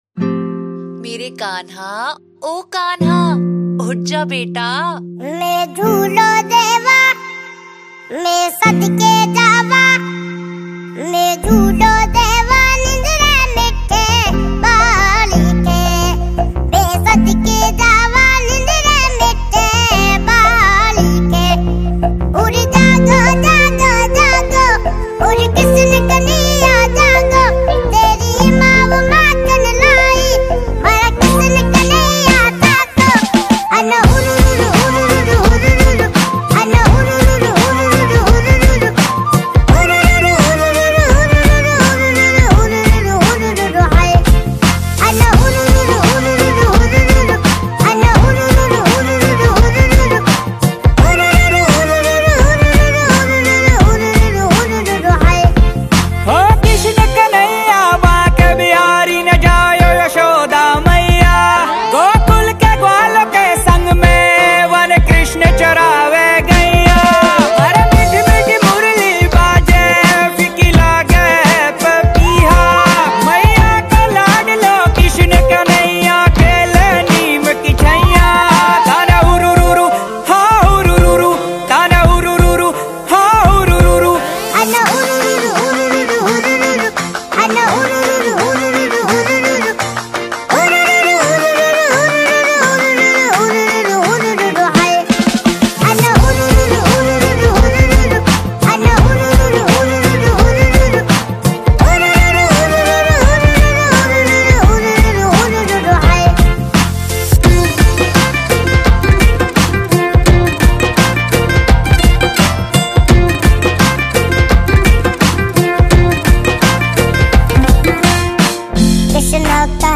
Devotional Songs